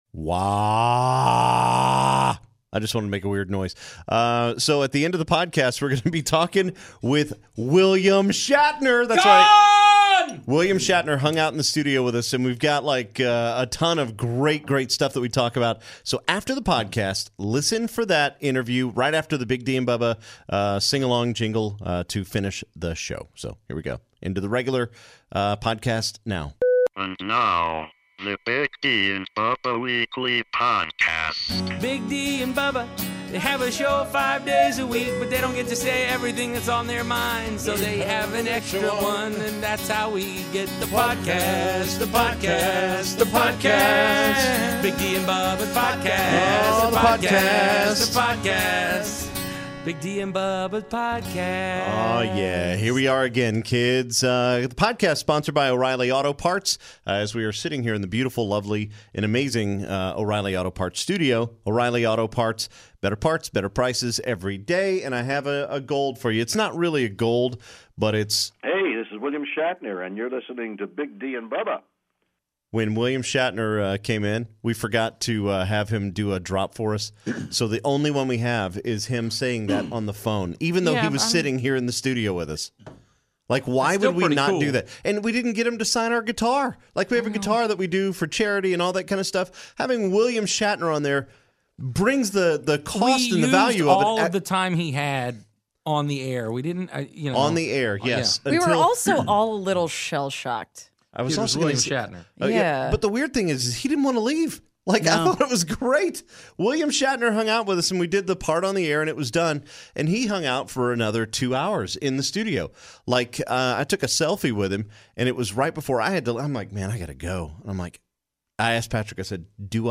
The LEGENDARY William Shatner and Country Music Hall of Famer Jeff Cook (Alabama) join us at the end of Weekly Podcast #236